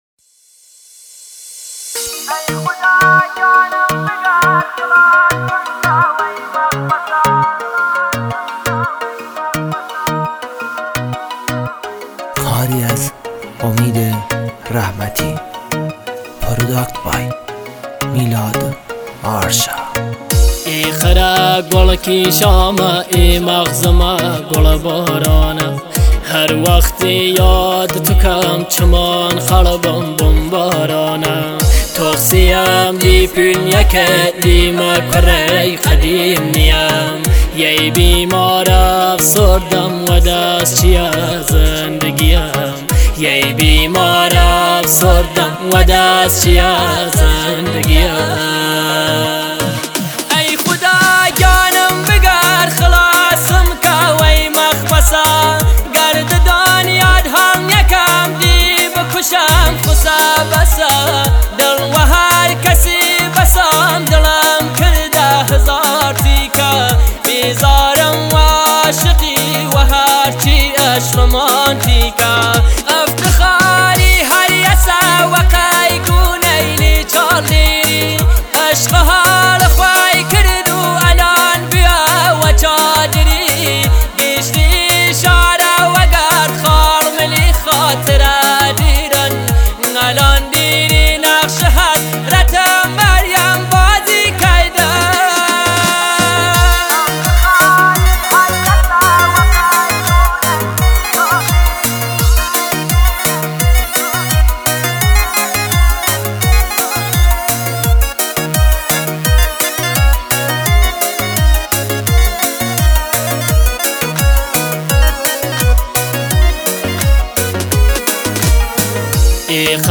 Remix